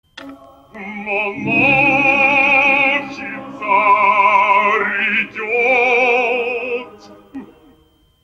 В монахи царь идёт. О каком реальном обычае пел Борис Годунов в опере Мусоргского?